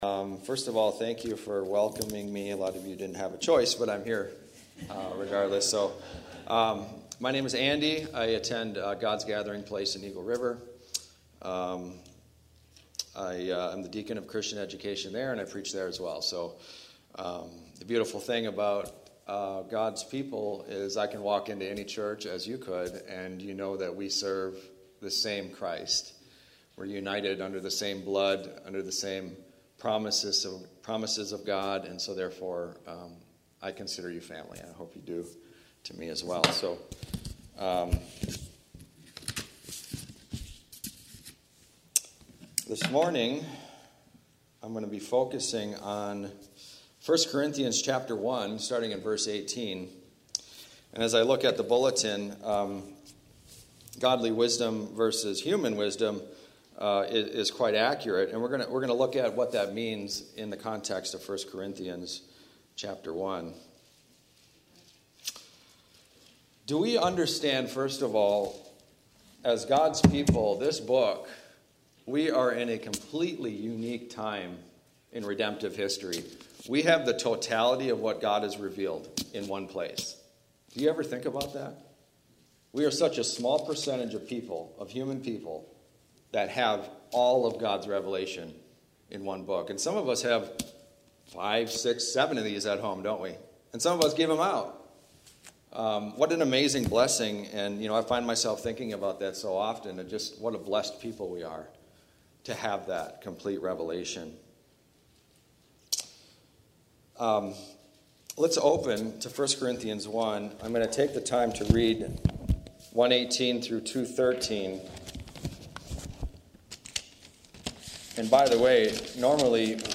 Sermons - Twin Lakes Bible Church